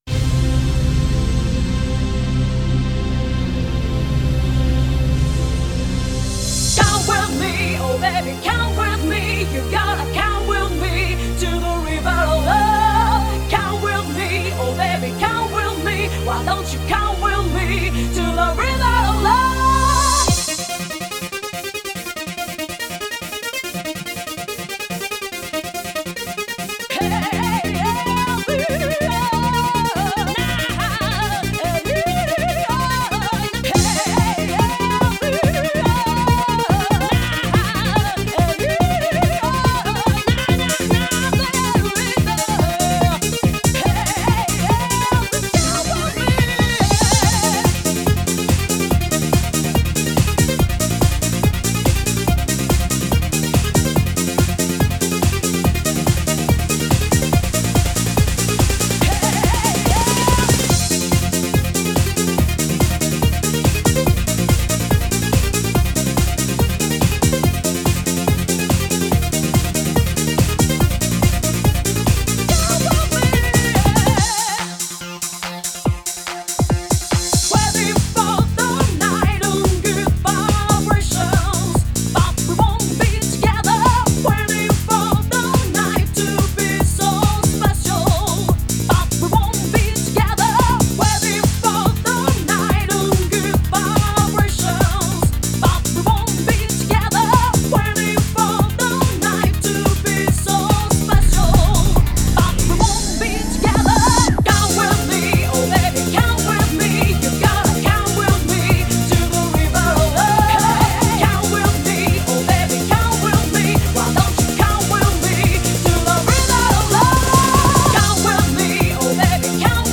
Genre: Italodance.